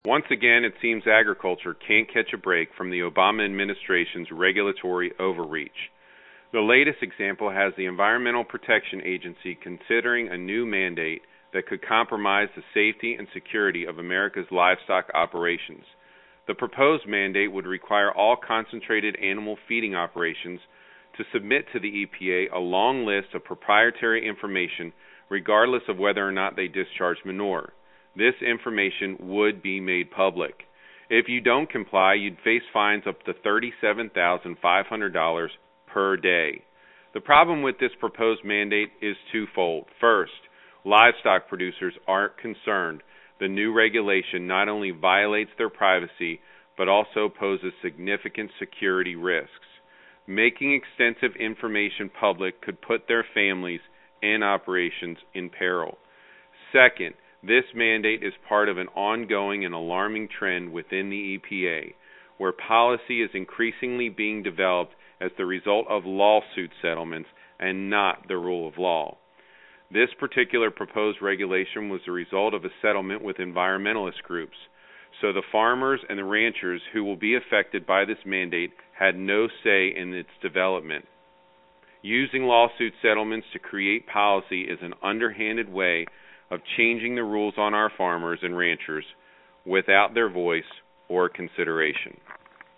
The Ag Minute, guest host Rep. Tom Rooney discusses the Environmental Protection Agency's (EPA) growing trend of developing public policy as the result of lawsuit settlements.
The Ag Minute is Chairman Lucas's weekly radio address that is released from the House Agriculture Committee.